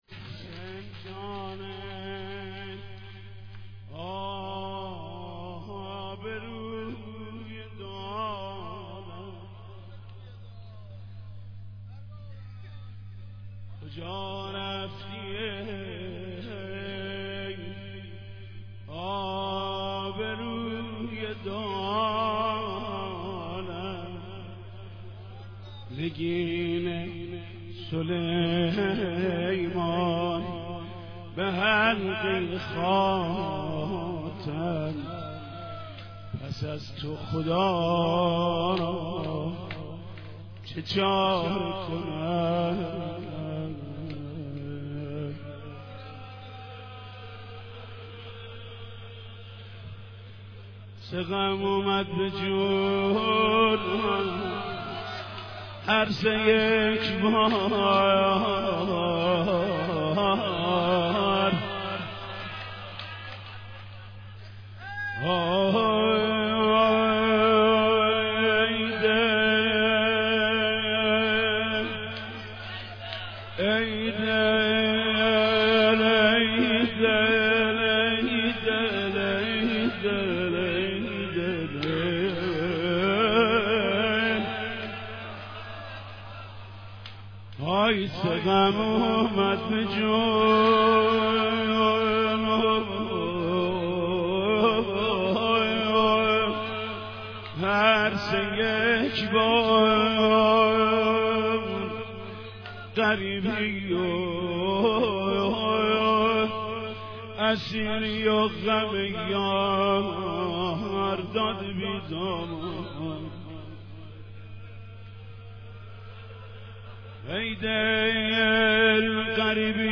مداحی
روضه خوانی